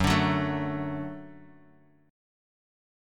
Gbm#5 chord